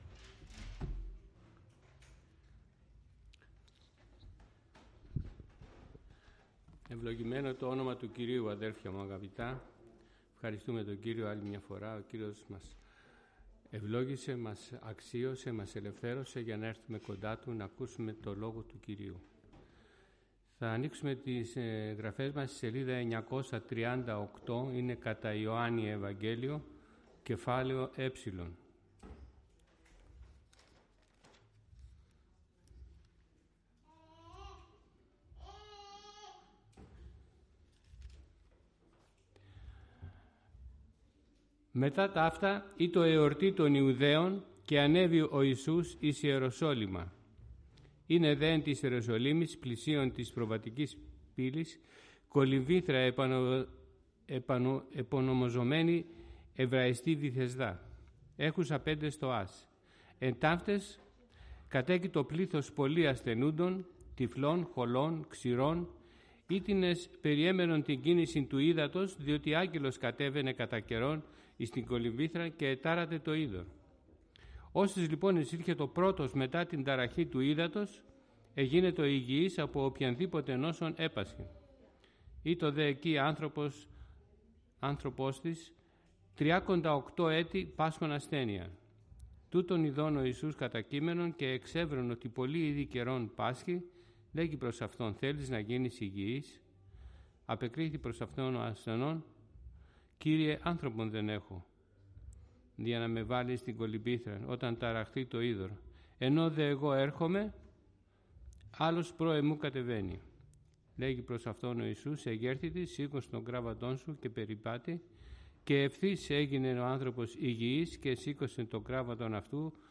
Κήρυγμα Ευαγγελίου